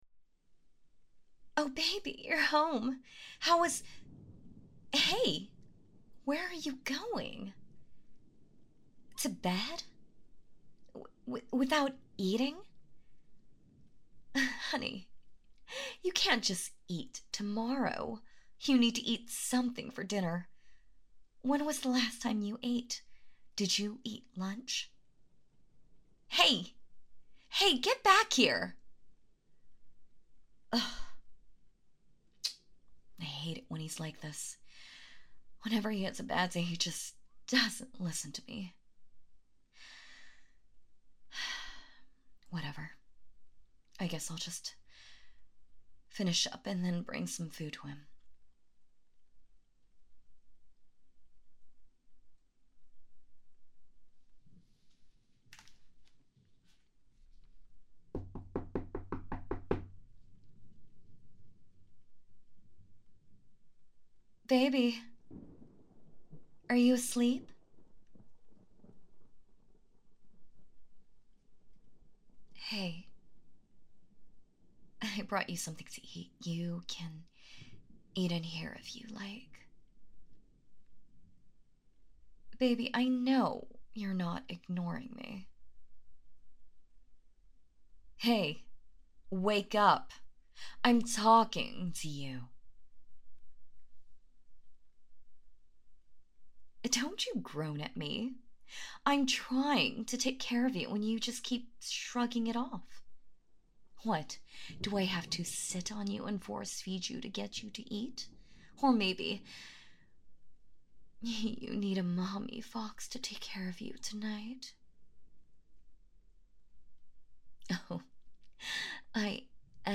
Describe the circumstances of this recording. Well....this is more comfort than sleep aid, but the last half is in bed, so I'm going to count it as that.